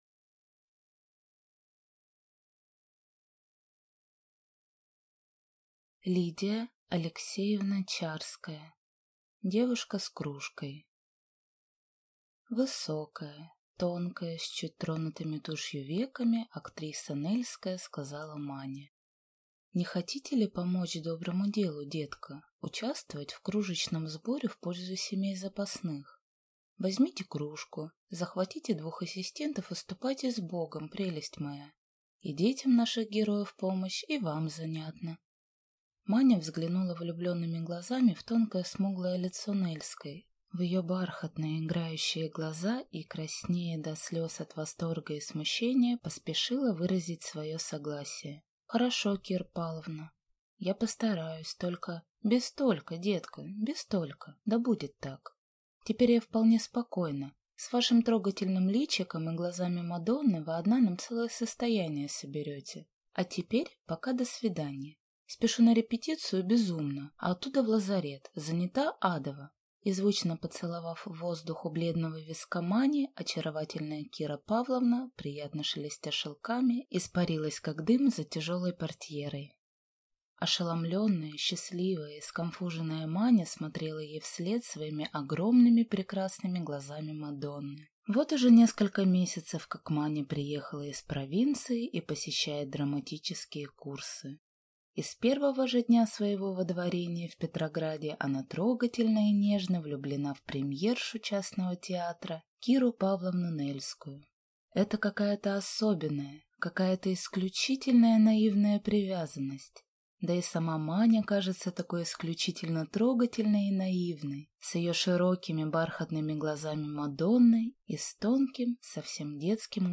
Аудиокнига Девушка с кружкой | Библиотека аудиокниг